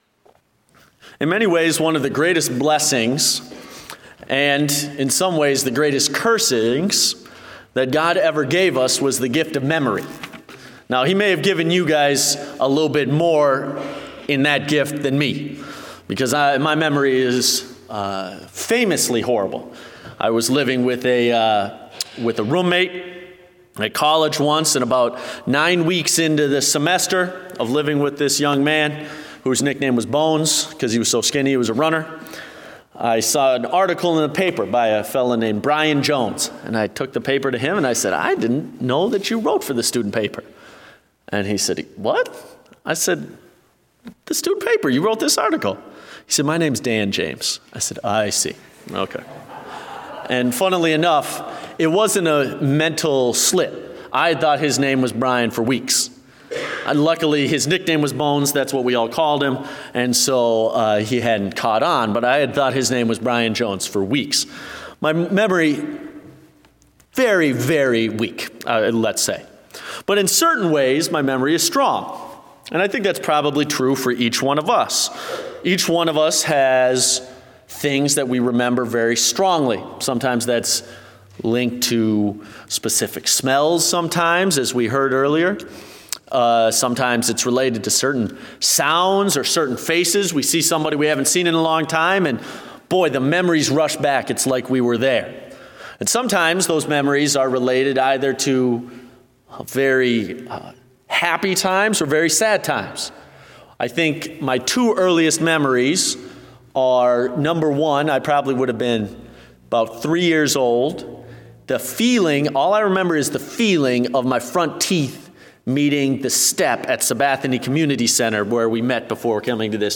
Date: March 5, 2017 (Evening Service)